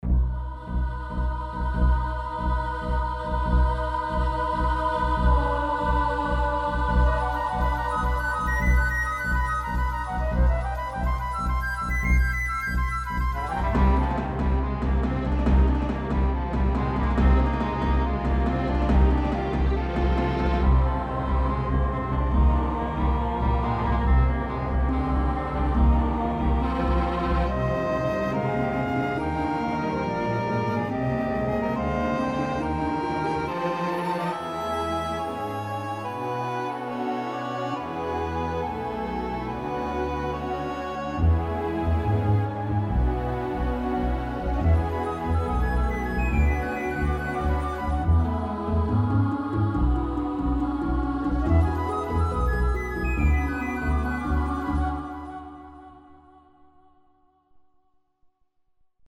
Emotion Au départ, la flute, le hautbois (?) et les percus me prennent bien car elles me rapellent pas mal du FF, donc ça augmente le coté émotion pour moi :)
Après le morceau apaisant je trouve, ca marche bien, sans prendre au bide non plus, mais ça va carrément ! 3/5
Orchestration Beaucoup de relances tout dans la finesse, l'utilisation des glissés metalliques passe bien pour ça, Après je t'ai connu plus grandiose et plus épique (le mot doit t'agacer maintenant, non ?) que sur ce morceau, mais j'accroche bien mais ce coté intimiste, le peu d'instrus, les choeurs très présents, c'est très bien mené ! 4/5
Sinon, tout est super propre, un petit truc à 27 et 35 secondes, tu as mis un violon qui fait un tremolo, il arrive un peu fort et sans fade in/out, ça fait un peu cut ( à 20 sec il était moins présent et ça passait mieux je trouve) Mais c'est vraiment pour pinailler. 4/5